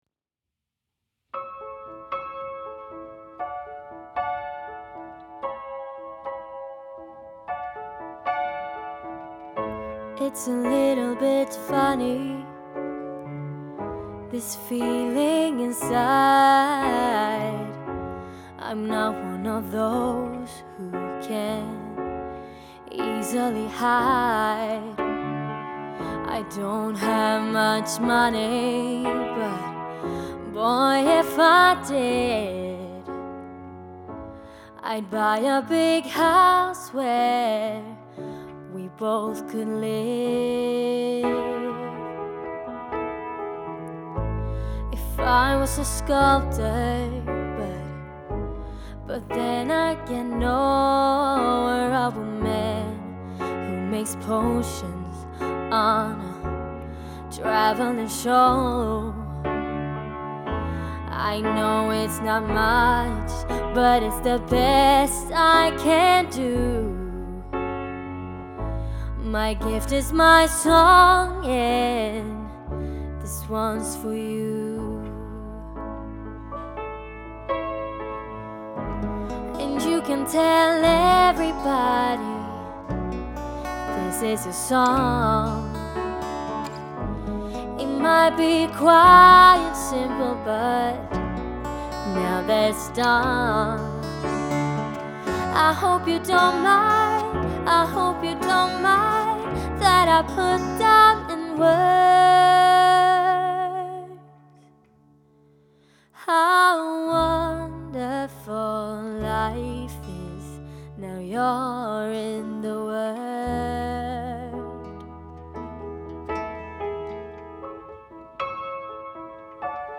Ett riktigt Coverband som spelar alla hitsen!
Rock, Soul och POP vi gör allt!